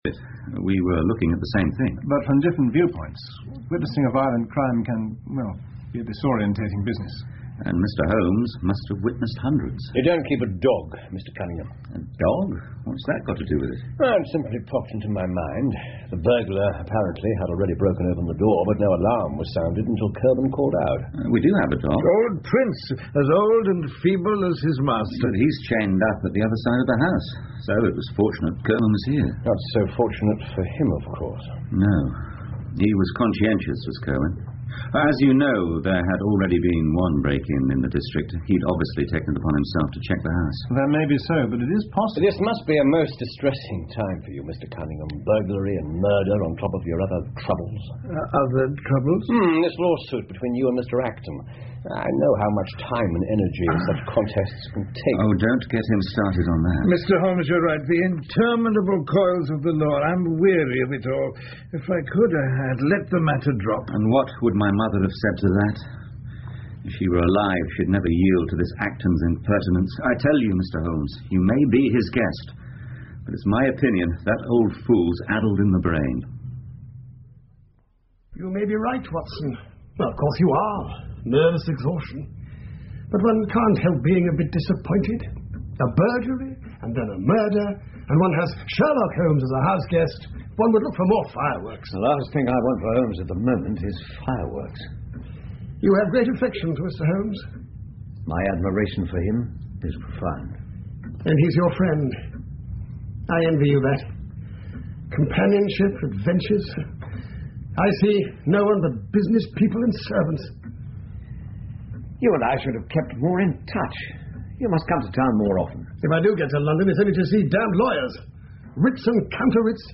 福尔摩斯广播剧 The Reigate Squires 5 听力文件下载—在线英语听力室
在线英语听力室福尔摩斯广播剧 The Reigate Squires 5的听力文件下载,英语有声读物,英文广播剧-在线英语听力室